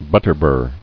[but·ter·bur]